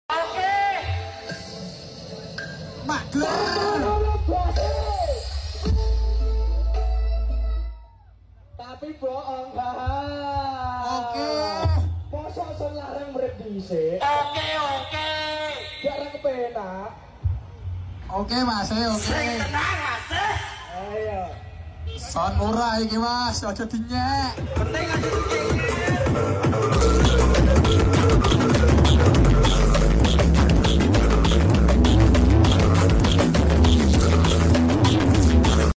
battle sound effects free download